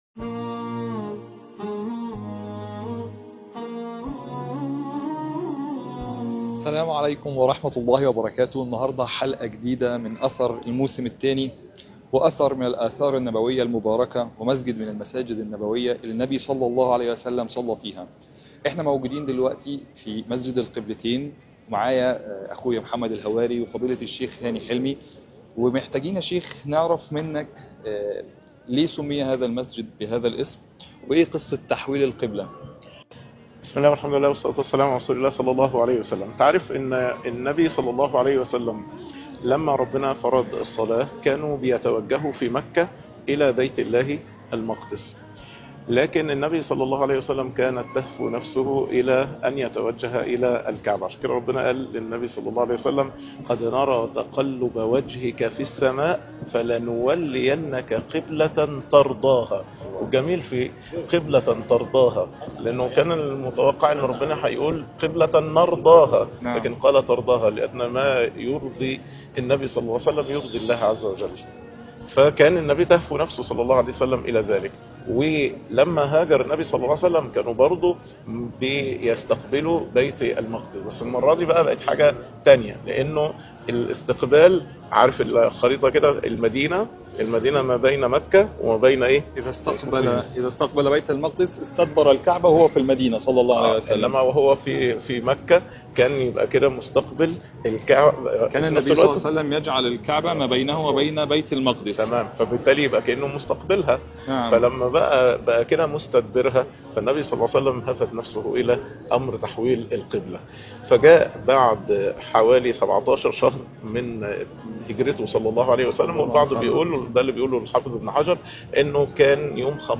المقرأة - سورة الأنبياء ص 325